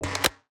UIClick_Denied Negative Mechanical Hollow 01.wav